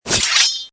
unsheath.mp3